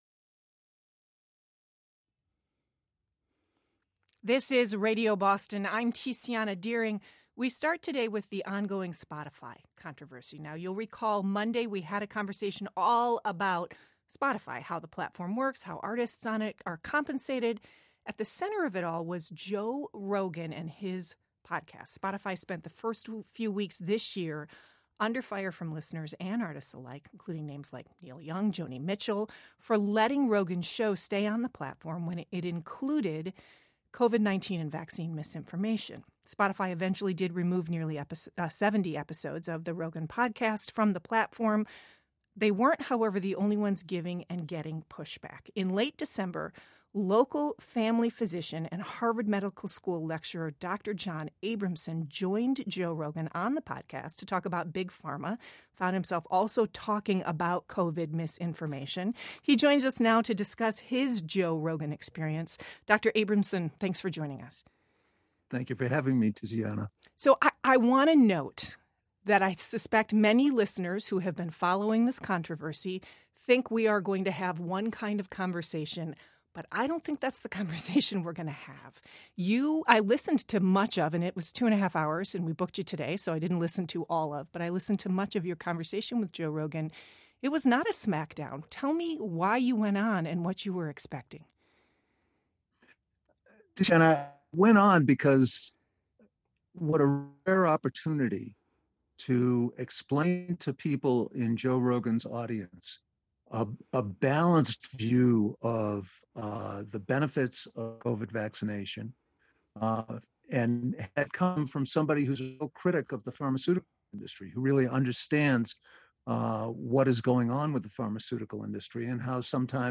He joined us to talk about his own Joe Rogan experience. This segment aired on February 10, 2022.